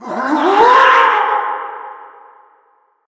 balloon_ghost_wail_03.ogg